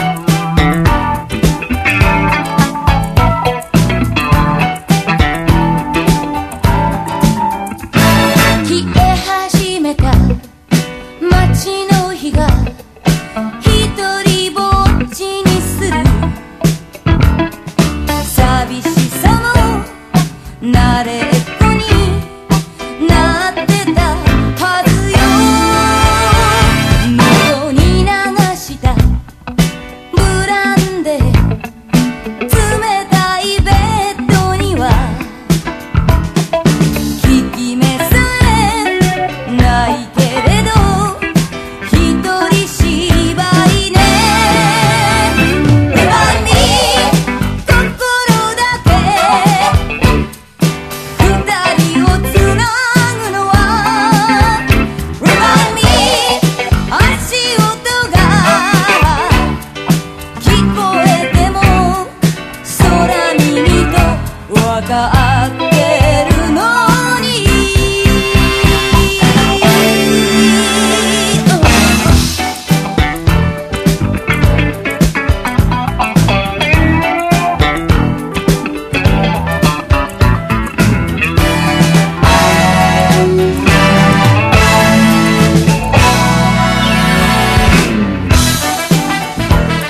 JAPANESE ACID FOLK / CITY POP
フォークの誠実さと歌謡メロディの叙情性、そしてサイケ～プログレを想起させる音像を持った楽曲が並びます。